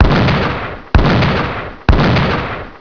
shelled by a destroyer (2.000 to)! We can not stay on the surface. The destroyer is too fast for us.
9shell.wav